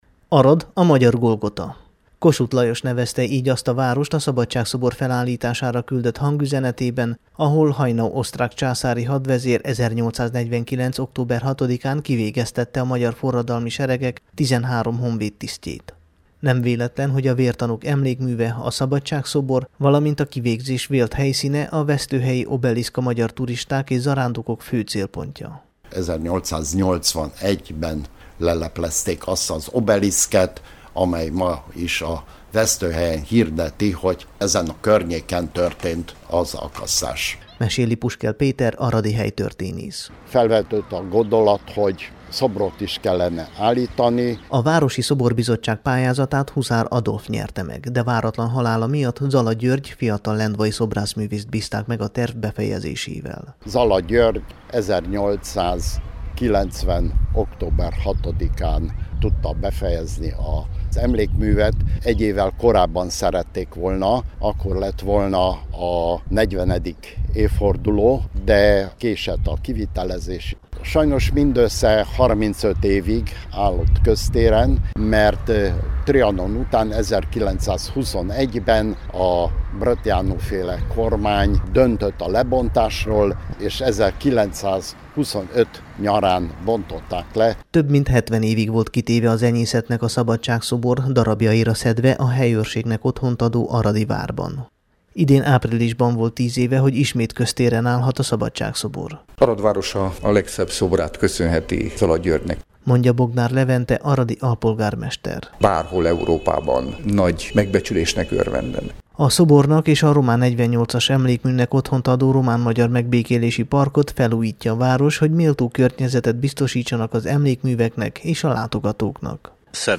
a Temesvári Rádió augusztus 30-i, szombati adása számára készült összeállításban